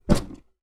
Punching Box Normal C.wav